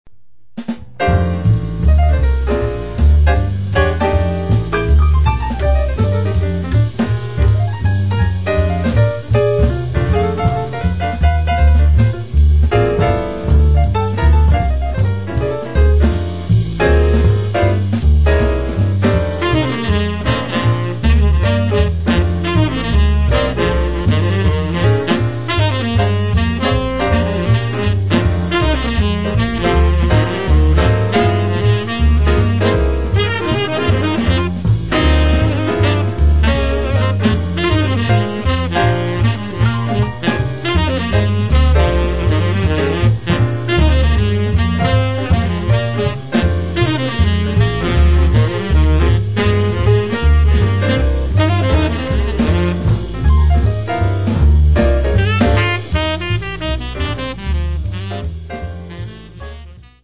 piano, electric piano
soprano saxophone, tenor saxophone
acoustic bass
drums